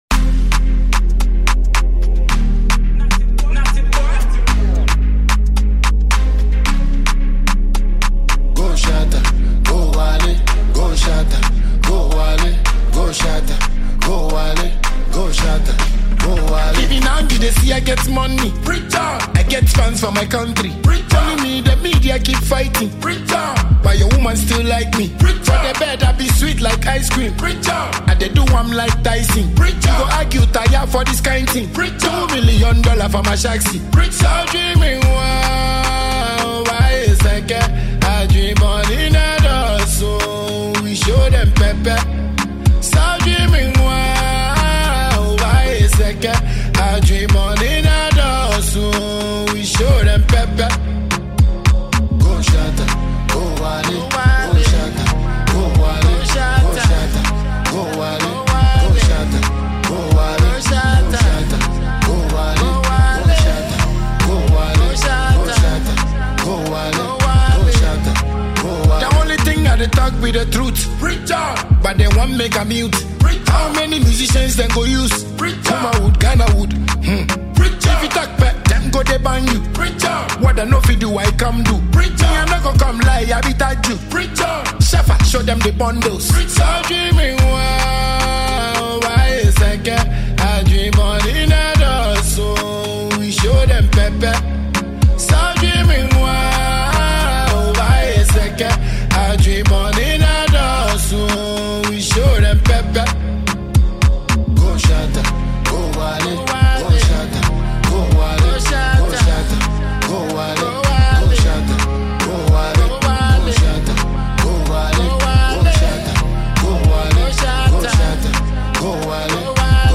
Ghana’s dancehall music legendary